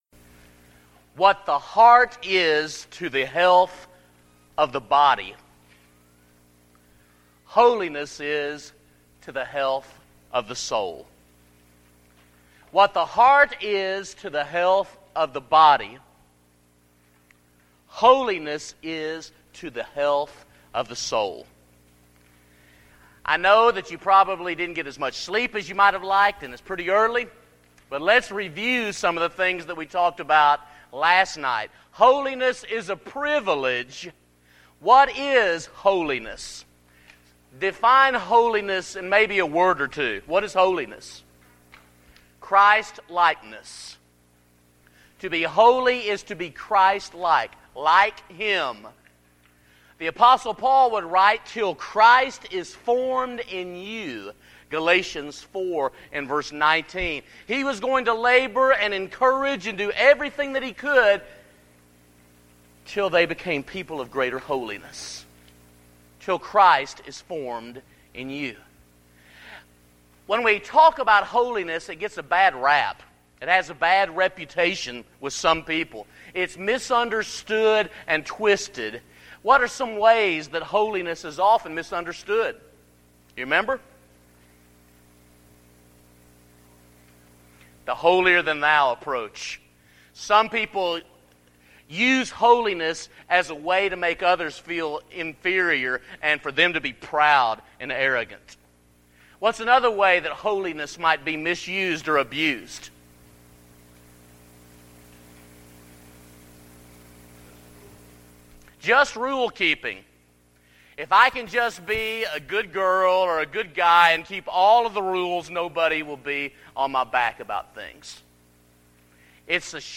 Event: Discipleship University 2012 Theme/Title: The Privilege, Pattern, & Pursuit of Holiness